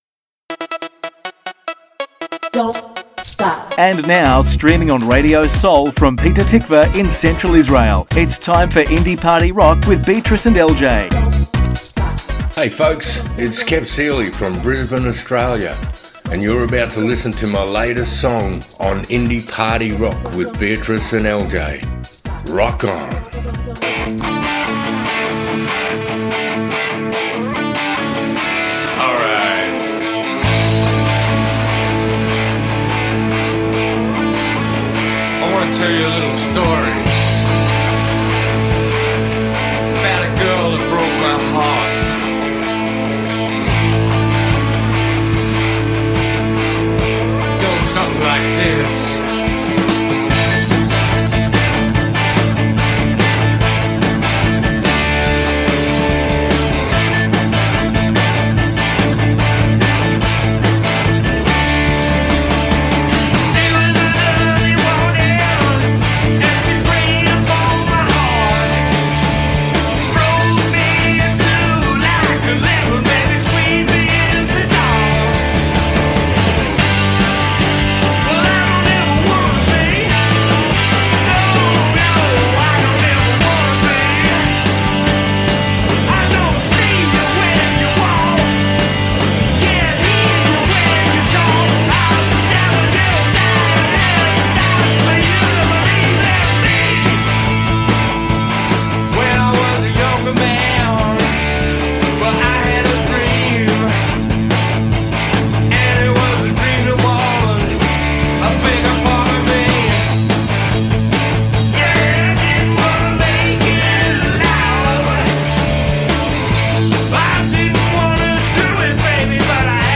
מוזיקת קאנטרי ואינדי עולמית - התכנית המלאה 22.11.24